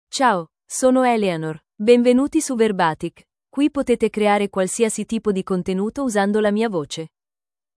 FemaleItalian (Italy)
EleanorFemale Italian AI voice
Eleanor is a female AI voice for Italian (Italy).
Voice sample
Female
Eleanor delivers clear pronunciation with authentic Italy Italian intonation, making your content sound professionally produced.